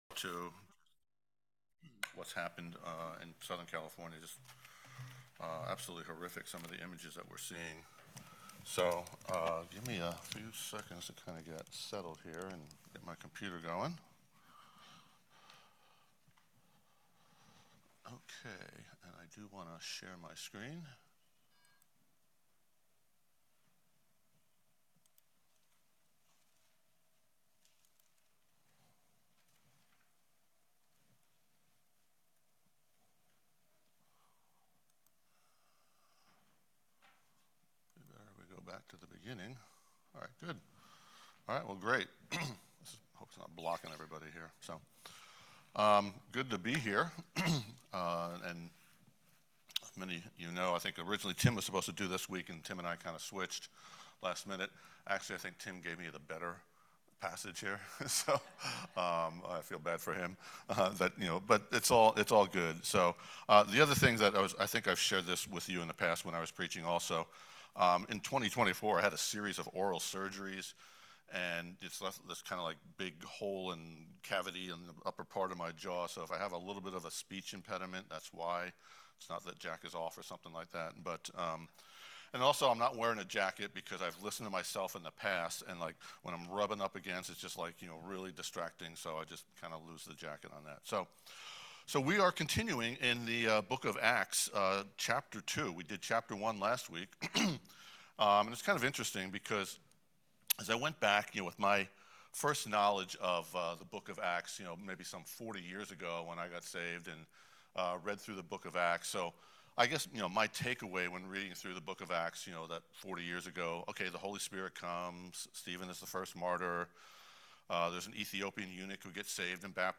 Acts 2:1-24 - Mountain Ridge Bible Chapel